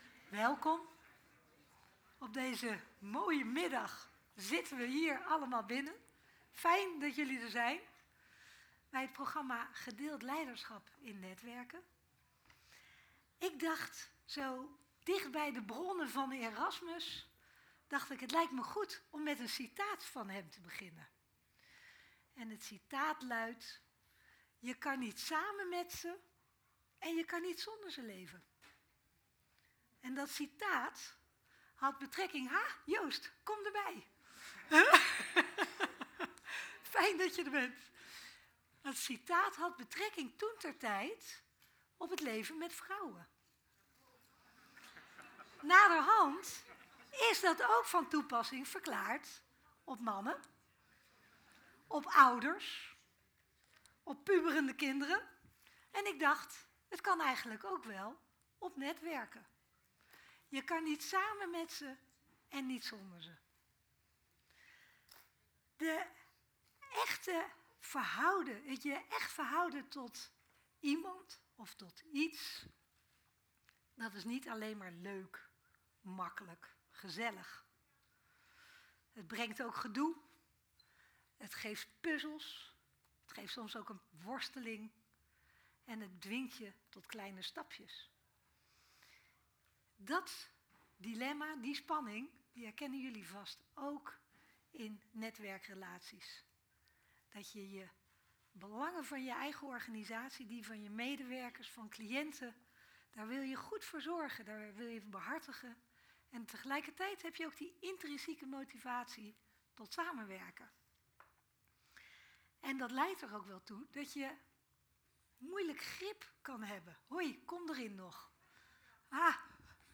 De moed en moeite die samenwerken kost, en wat het oplevert. Experts en ervaringsdeskundigen vertelden erover tijdens de studiedag ‘Gedeeld leiderschap in netwerken’ op 14 mei 2024.
Registratie middag - voor de pauze